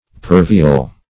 Search Result for " pervial" : The Collaborative International Dictionary of English v.0.48: Pervial \Per"vi*al\, a. [See Pervious .]